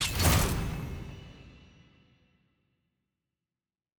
sfx-exalted-hub-summon-x10-click.ogg